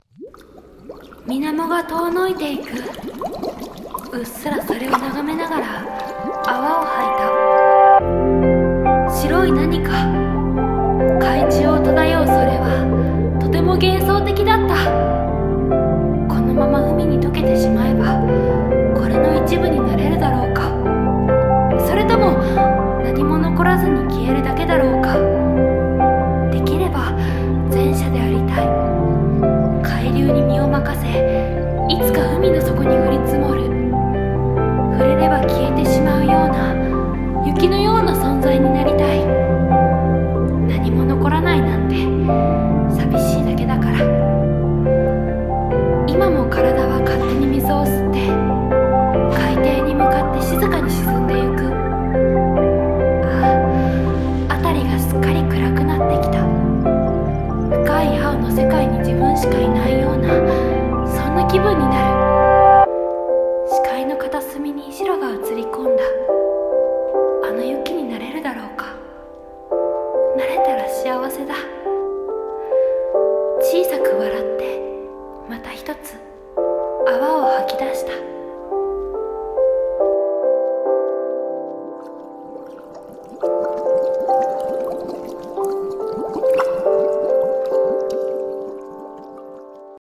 【一人声劇】海雪にとける